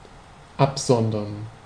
Ääntäminen
IPA : /sɪˈkɹiːt/